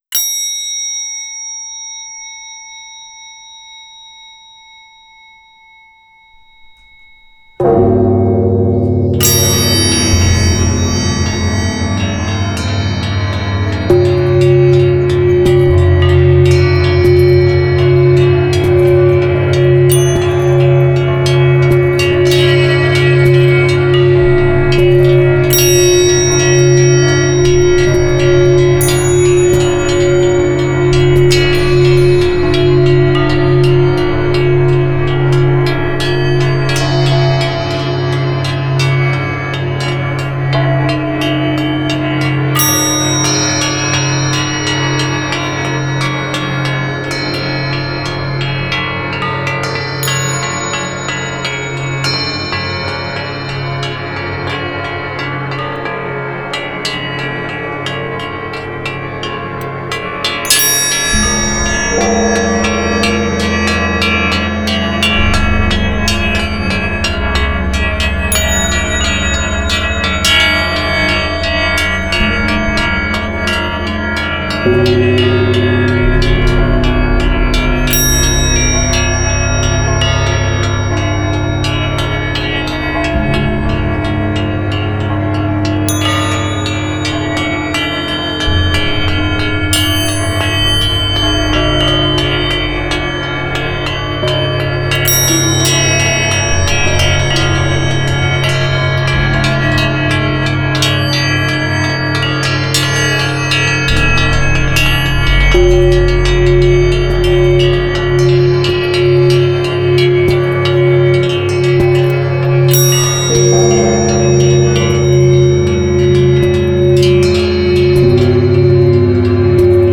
18-channel studio